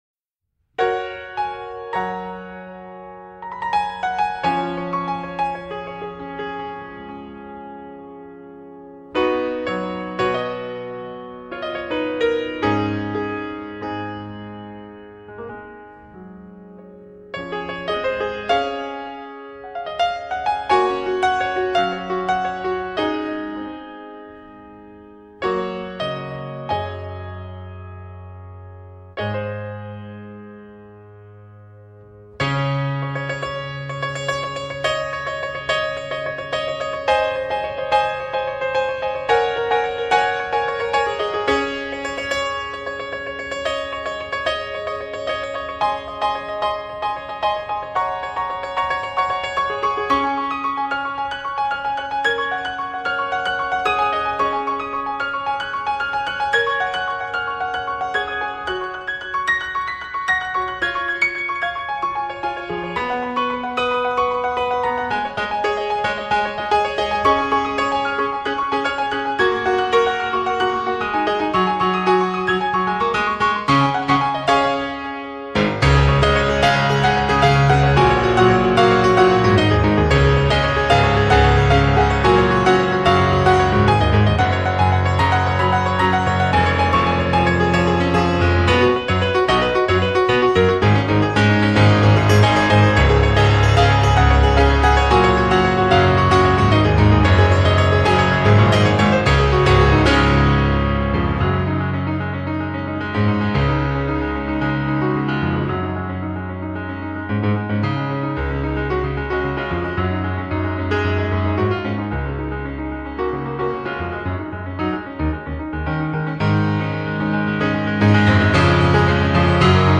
PIANO MUSIC